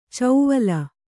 ♪ cauvala